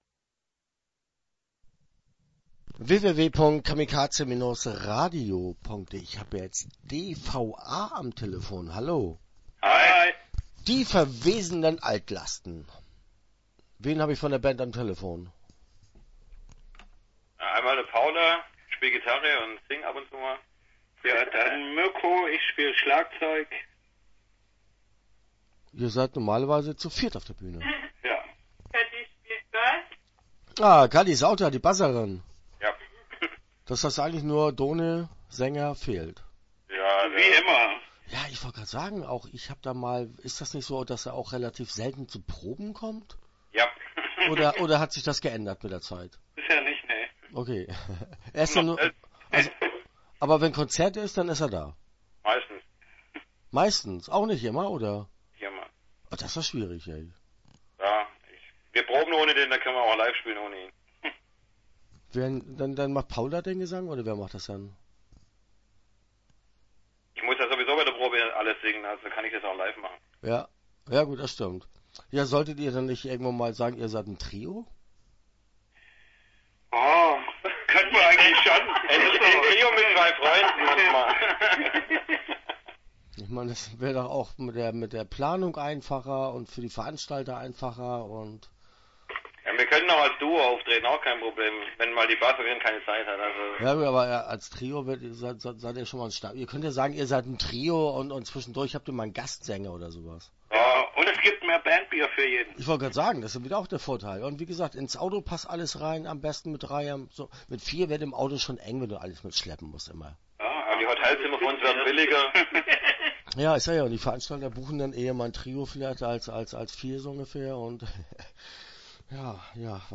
Start » Interviews » D.V.A. - Die verwesenden Altlasten